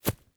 Terrarum / assets / mods / basegame / audio / effects / steps / GRSS.5.wav